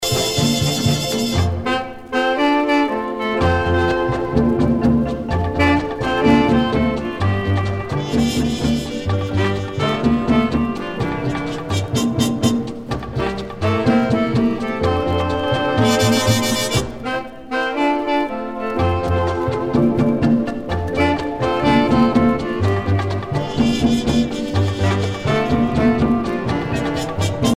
danse : mambo